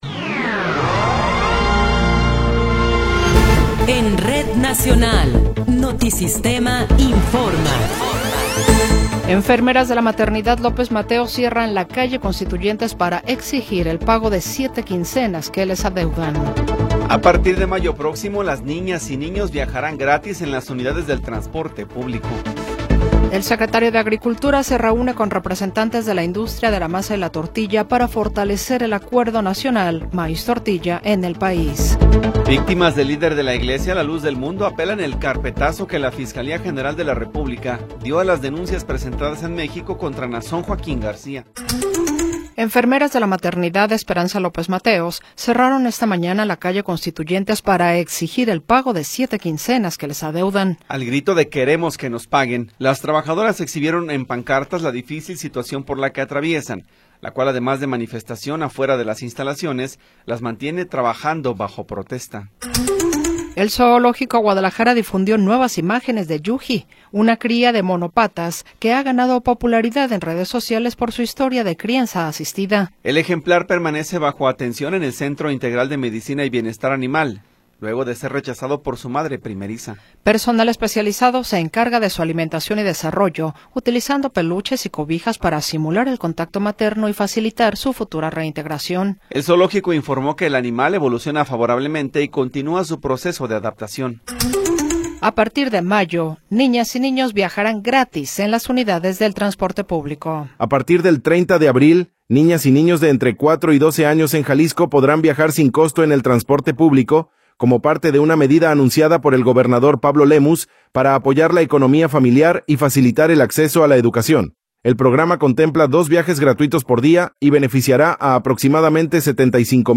Noticiero 14 hrs. – 15 de Abril de 2026
Resumen informativo Notisistema, la mejor y más completa información cada hora en la hora.